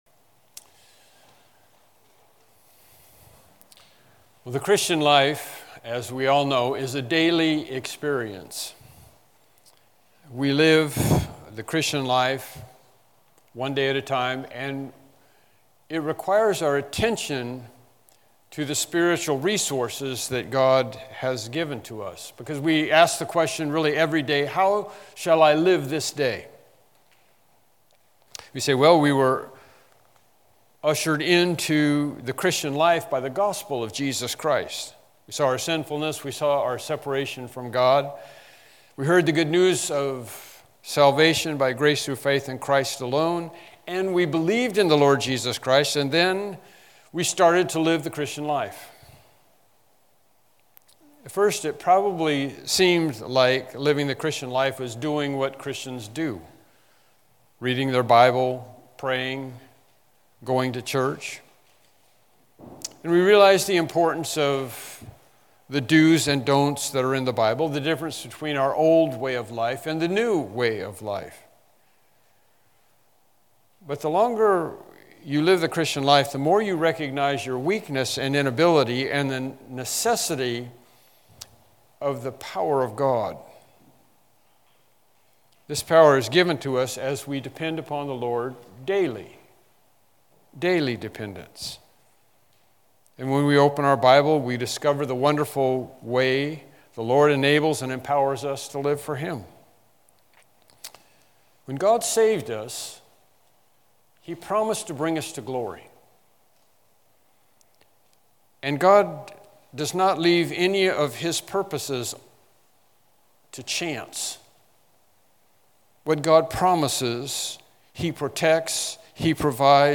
Galatians Passage: Galatians 5:21-22 Service Type: Morning Worship Service « Lesson 18 The King Prophesies HBC & TBC Hymn Sing